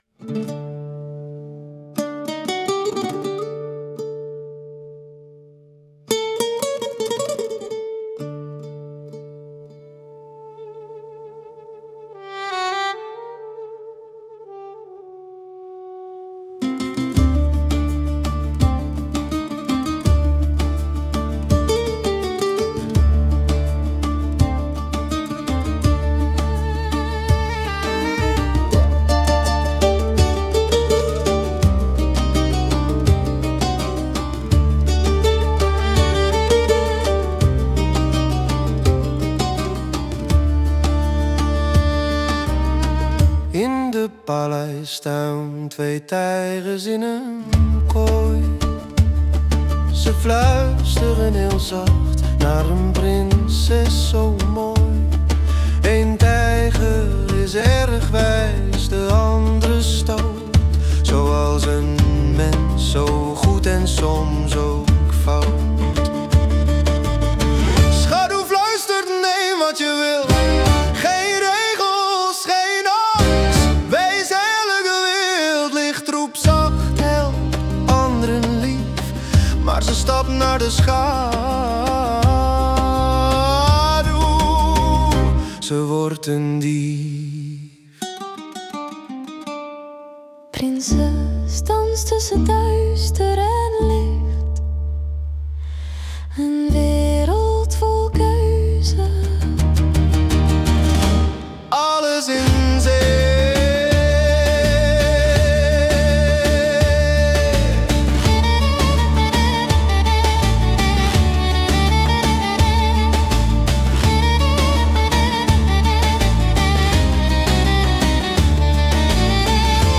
Created from original concepts/texts with AI.